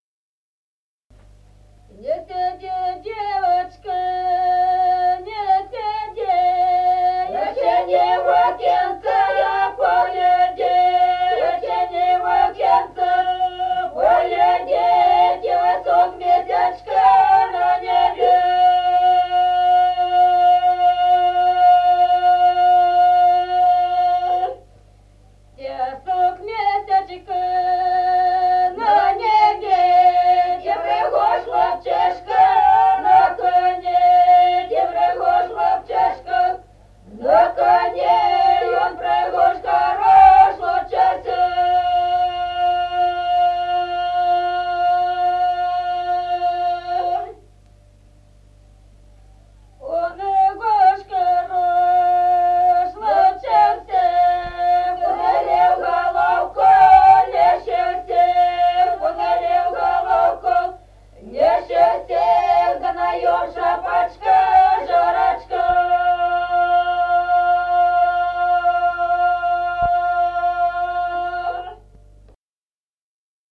Народные песни Стародубского района «Не сиди, девочка», свадебная.
с. Остроглядово.